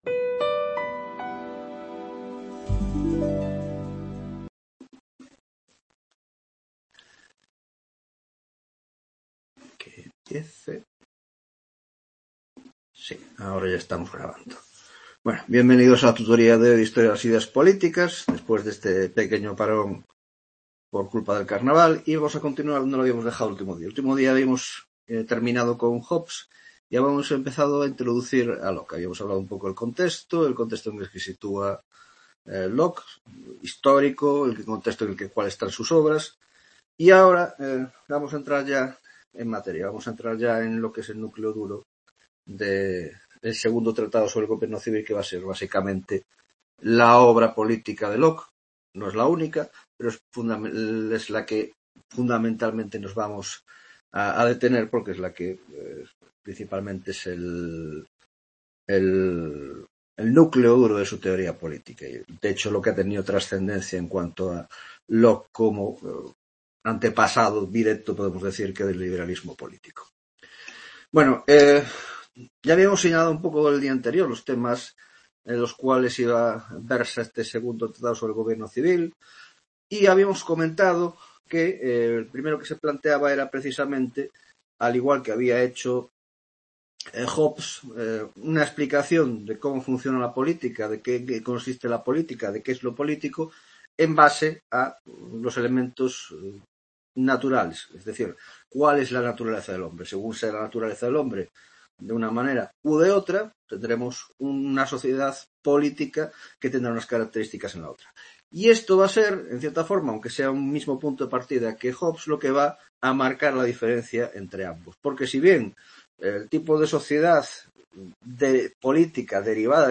3ª Tutoria de Historia de las Ideas Políticas II (Grado de Ciéncias Políticas ) - John Locke y los Origenes del Liberalismo